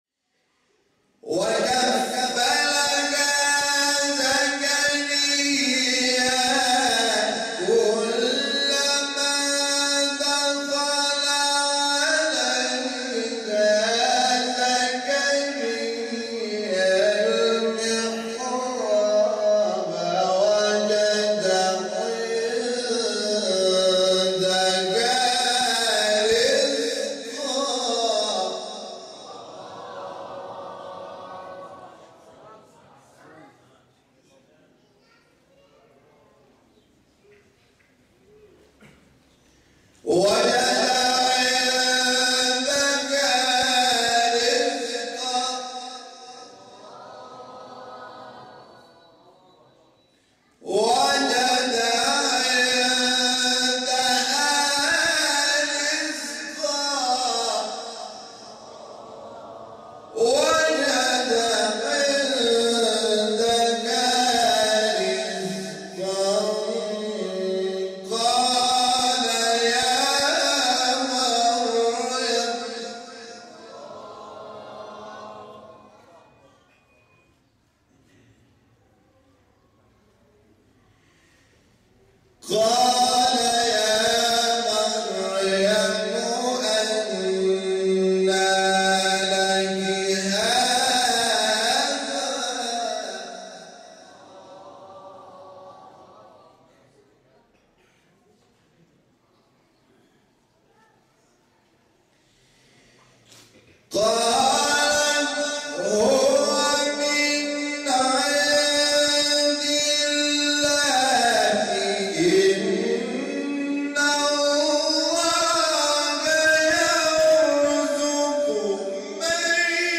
مقام : رست